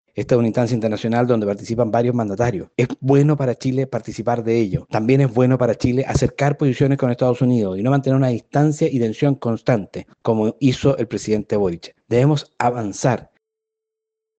En la misma línea, el parlamentario de la UDI, Felipe Donoso, señaló que es positivo que Chile pueda participar en estas instancias y acercar posiciones con EE.UU., pese a las tensiones por la revocación de visas.